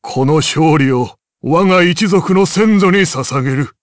Dakaris-Vox_Victory_jp.wav